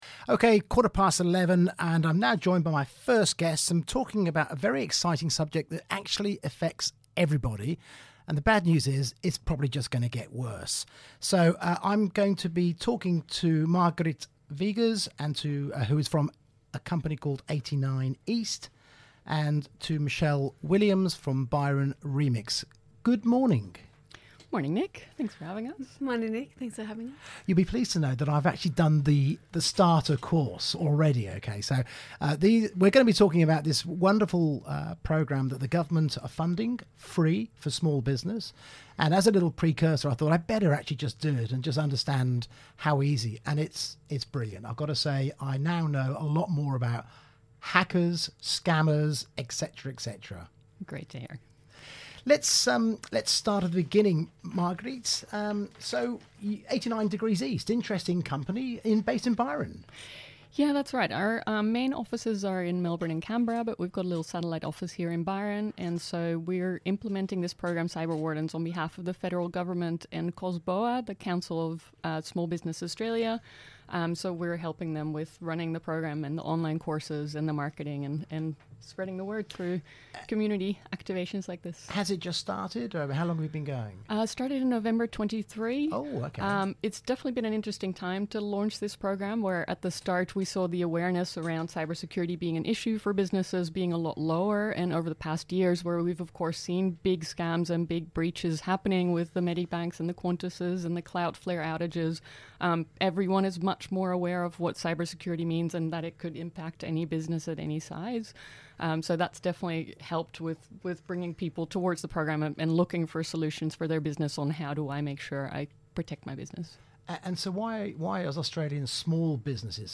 Cyber Security Interview on Bay FM Radio